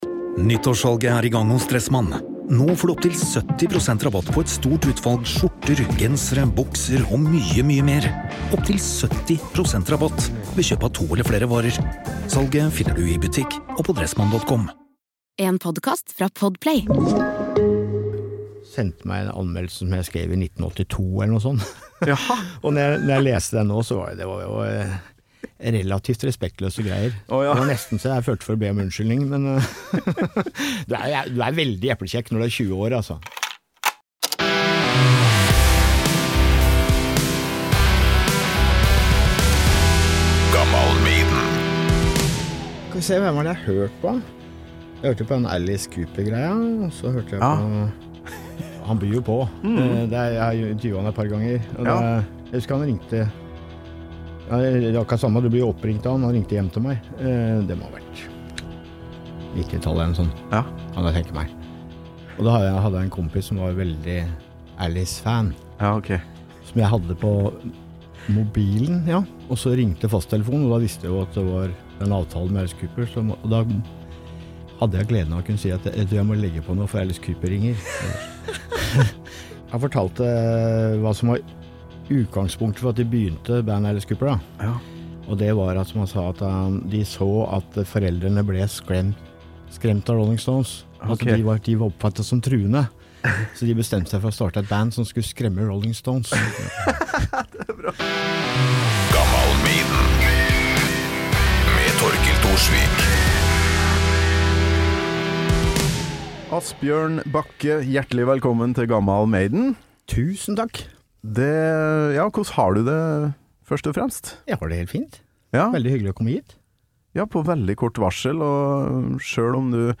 Og hvordan er det å lese 40 år gamle musikkanmeldelser i dag? (NB: Det finnes selvfølgelig liveopptak av Twilight Zone. Bra opptak er det også, som du får høre en liten snutt av helt til slutt i episoden) Mehr